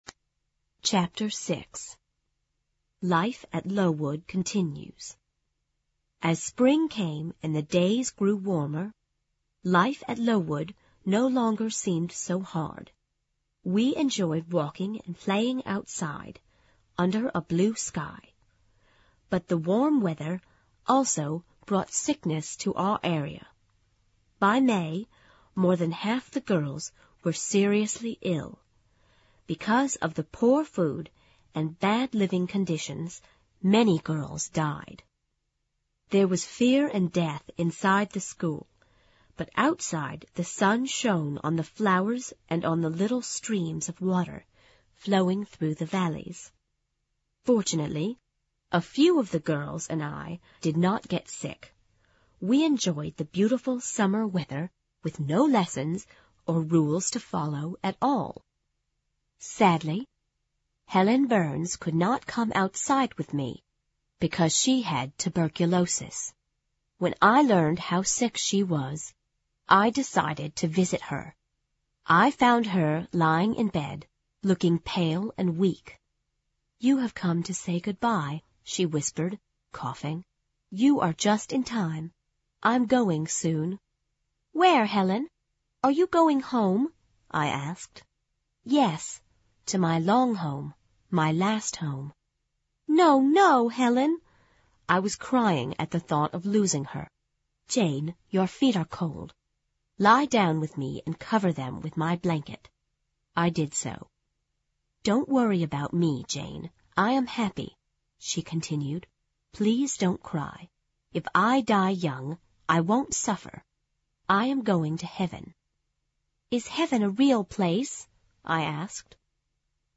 有声名著之简爱Jene Eyer Chapter6 听力文件下载—在线英语听力室